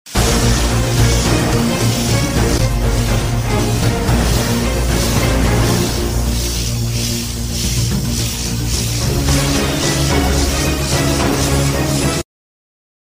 ❤Networking joy: Clean server room sound effects free download